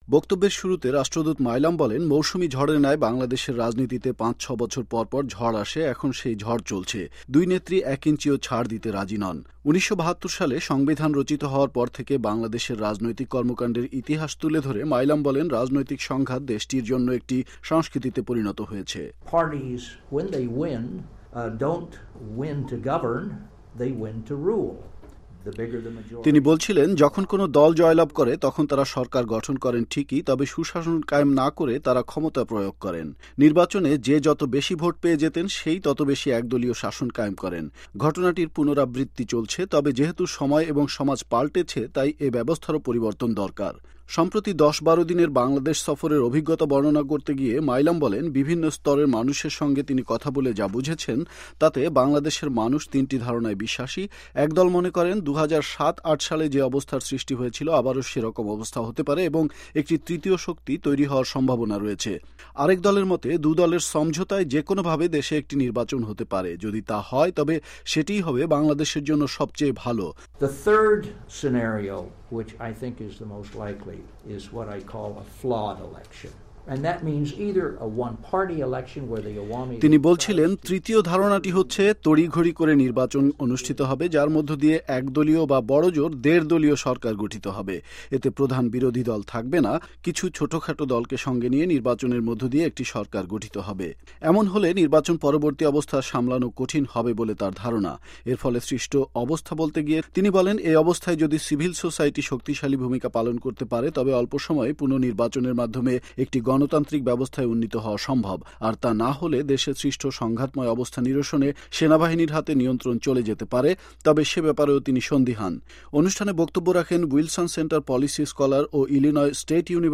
বুধবার ওয়াশিংটন ডিসির থিংক ট্যাংক প্রতিষ্ঠান উড্রো উইলসন সেন্টার আয়োজিত বাংলাদেশ বিষয়ক এক আলোচনা সভায় বক্তব্য রাখেন বাংলাদেশে নিযুক্ত যুক্তরাষ্ট্রের সাবেক রাষ্ট্রদূত উইলয়াম বি মাইলাম।